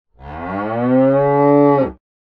animalia_cow_random_1.ogg